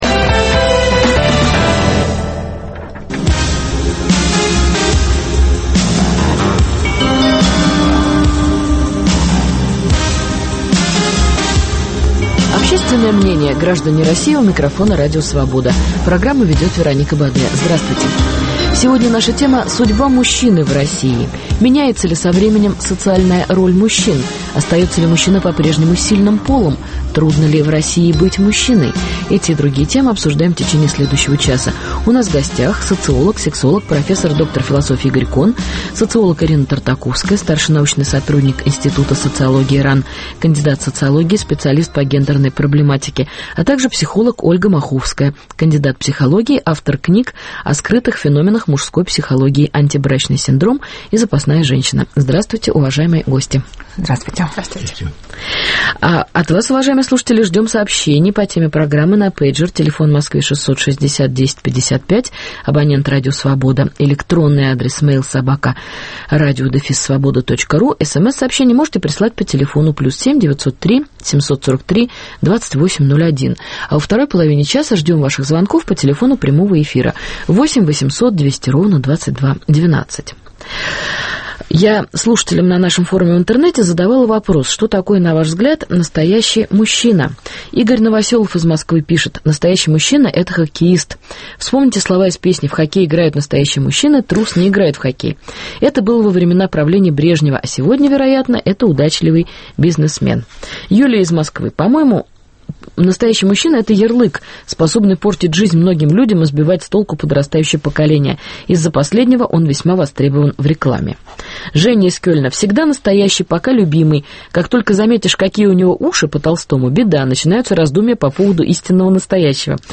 социолог, сексолог Игорь Кон.